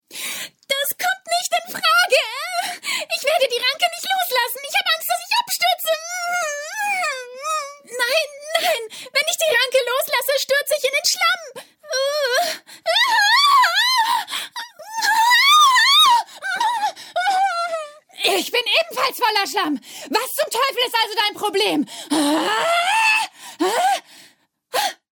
Sprecherin, Synchronsprecherin